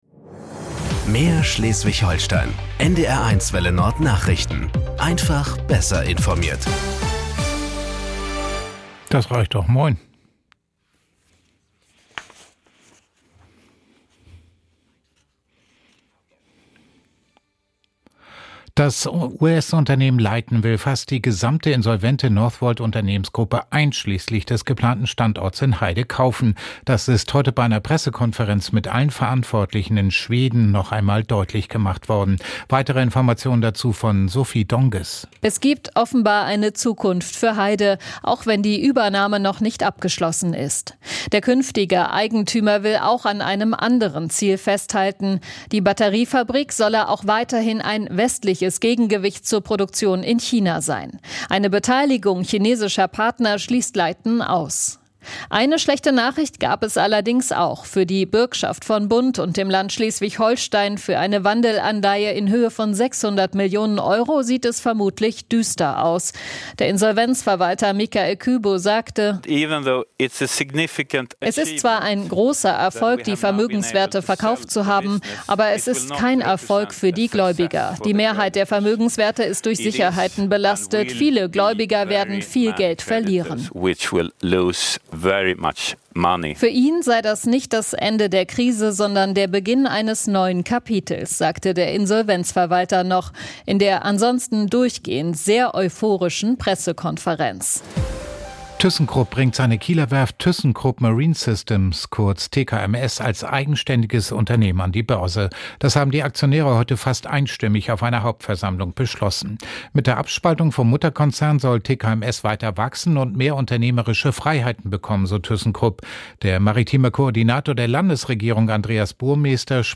Nachrichten 13:00 Uhr.